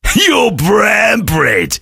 sam_kill_vo_04.ogg